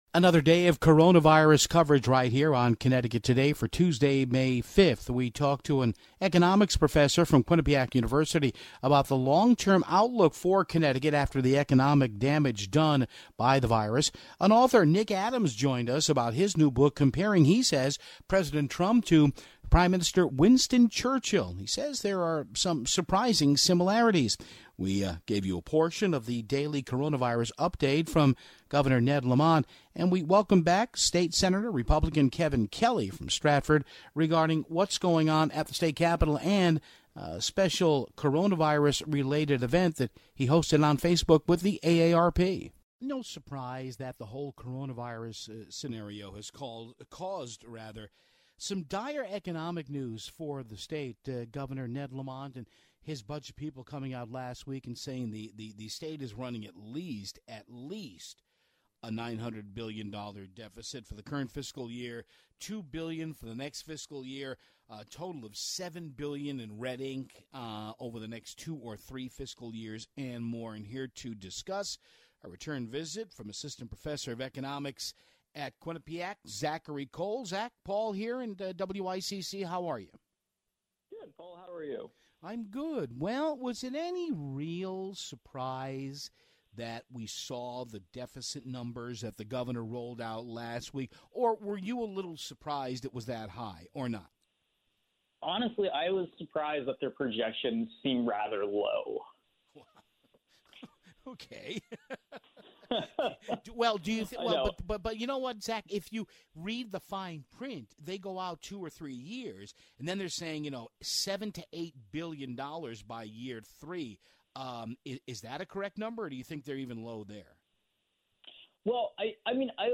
We had segments for you of course of the daily coronavirus update from Governor Ned Lamont. Finally, we welcomed back Republican State Sen. Kevin Kelly to talk about how things look up at the state capital and a recent event he hosted with AARP on Facebook!